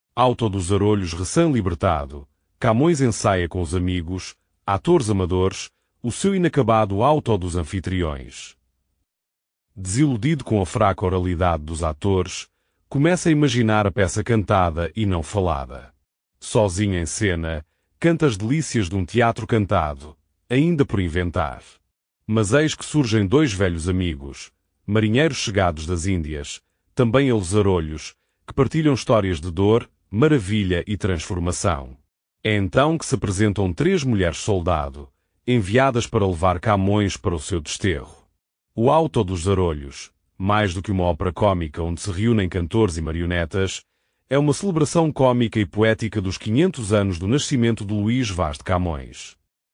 este áudio guia possui 13 faixas e duração de 00:19:08, num total de 13.6 Mb